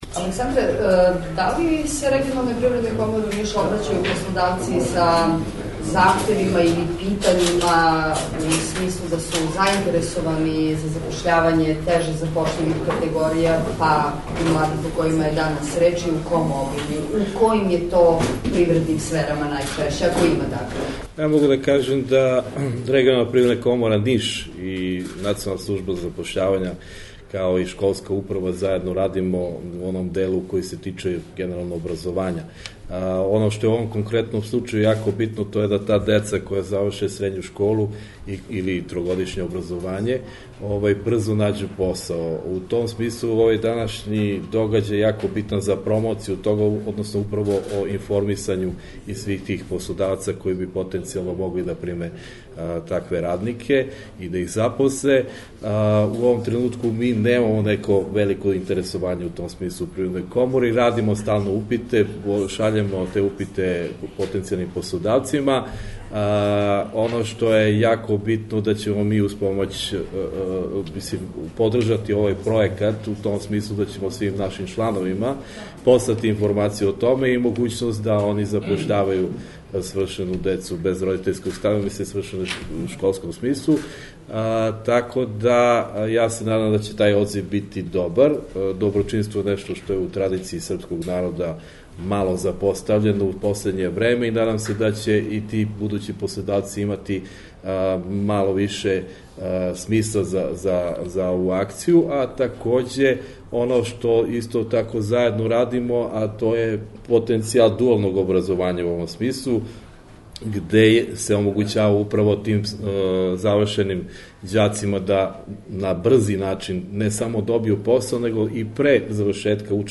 11.07.2017. – Mladi bez roditeljskog staranja danas su imali priliku da se predstave potencijalnim poslodavcima na skupu „Predstavi sVe“ održanom u Skupštini grada Niša. U okviru istog događaja, članovi lokalnog privrednog sektora su, osim upoznavanja sa kandidatima, mogli da saznaju sve o subvencijama i značaju zapošljavanja ljudi iz ove kategorije.